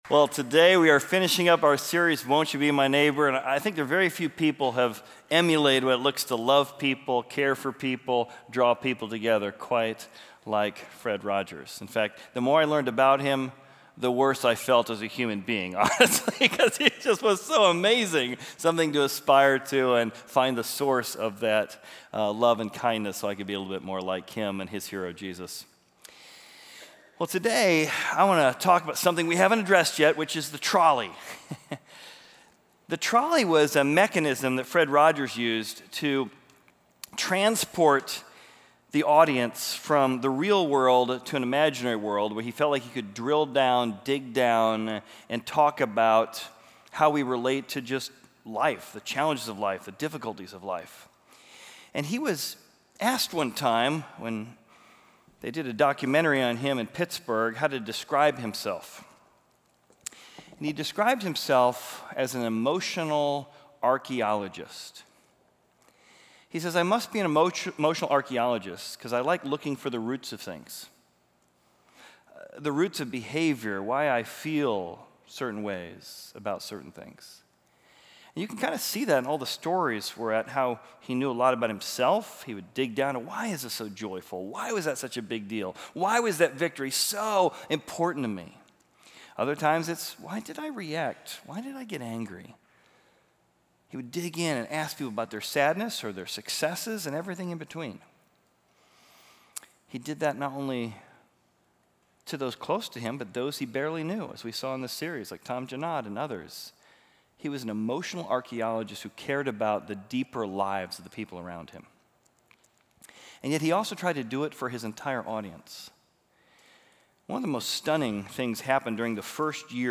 Exploring Service / Won't You Be My Neighbor? / Can You Say… "Emotional Archeologist?"